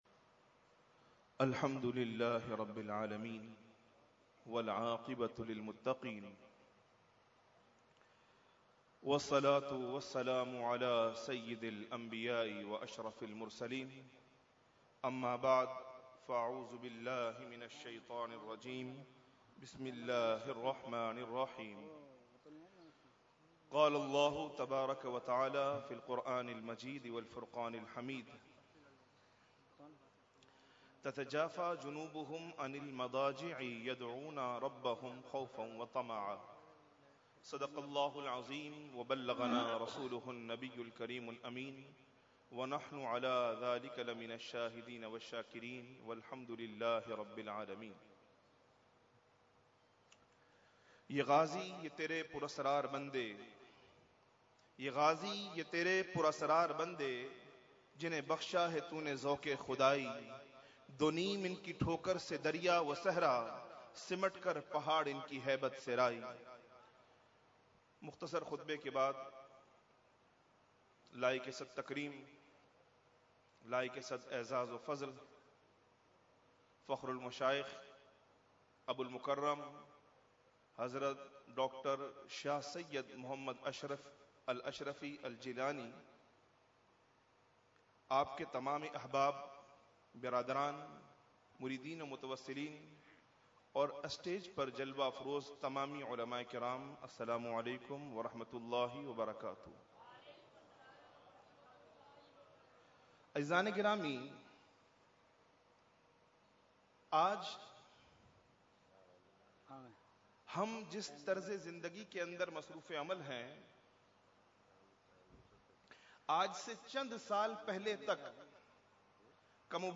Category : Speech | Language : UrduEvent : Urs Makhdoome Samnani 2016